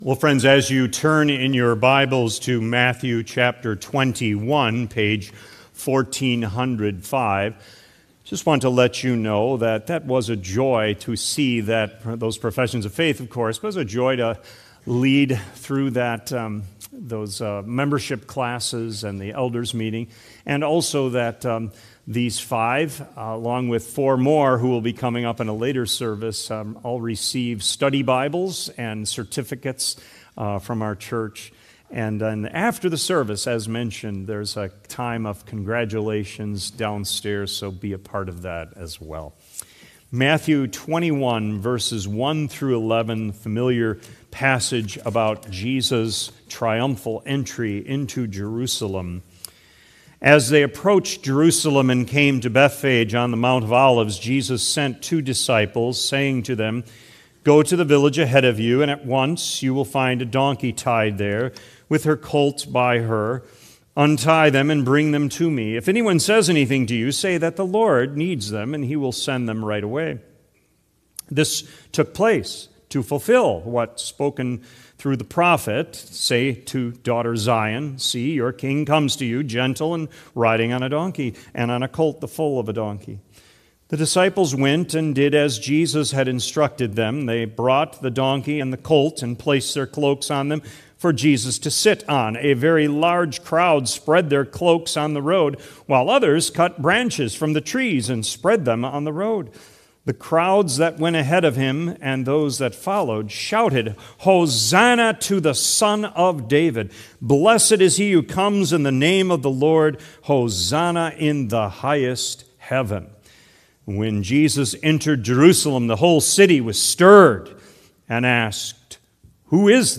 Sermon Recordings | Faith Community Christian Reformed Church